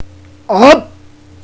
So in my vast amount free time, i have recorded myself saying a number of things retardely.
More wierd noises